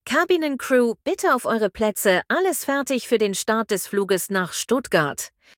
CrewSeatsTakeoff.ogg